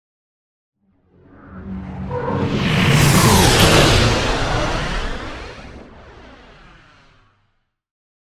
Whoosh large heavy
Sound Effects
No
dark
intense
whoosh